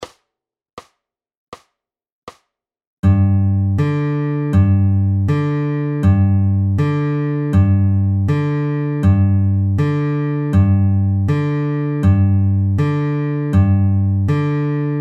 EXAMPLE 1 - G ALTERNATE BASS